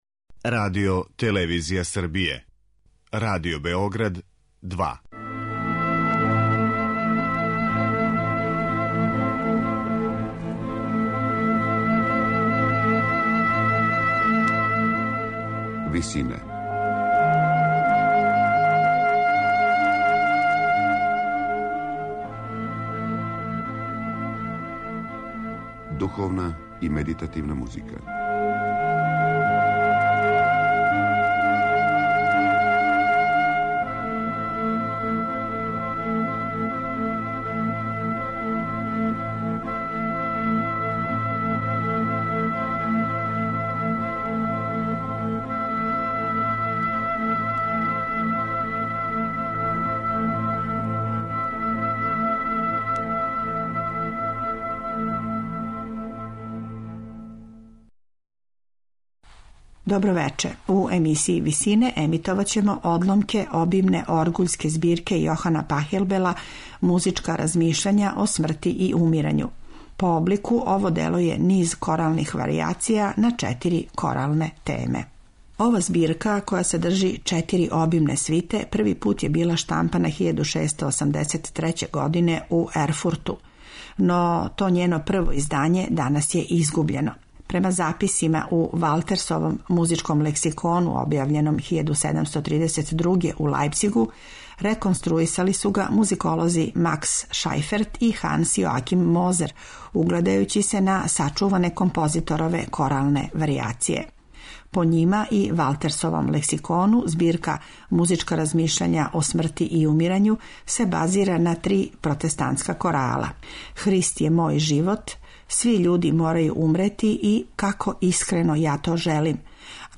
По облику ово дело је низ коралних варијација на четири коралне теме.
Оргуљаш